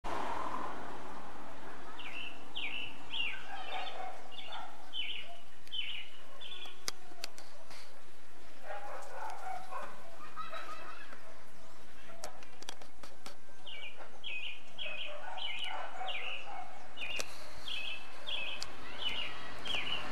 Zorzal Piquinegro (Turdus ignobilis) - EcoRegistros
Nombre en inglés: Black-billed Thrush
Localización detallada: El Coca
Condición: Silvestre
Certeza: Fotografiada, Vocalización Grabada